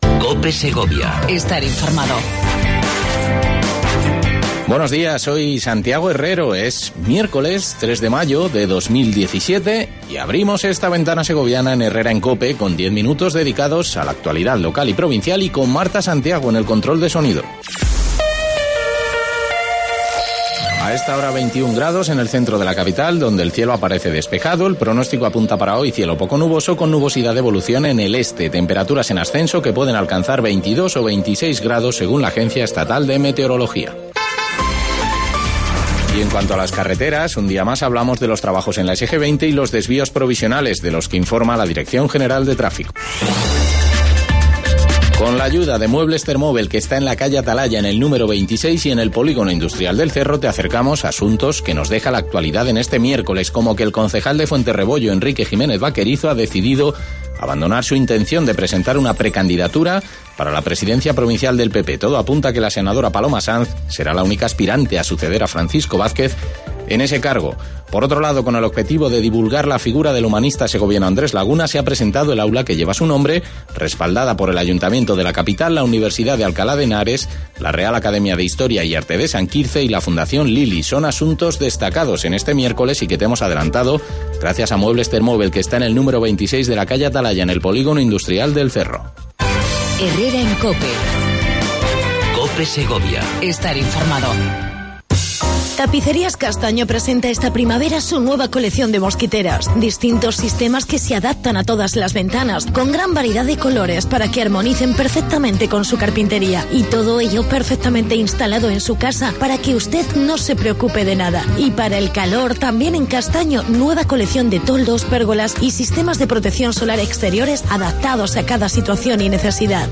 Entrevista sobre el Britihs Council en Segovia